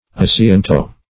Assiento \As`si*en"to\, n. [Sp. asiento seat, contract or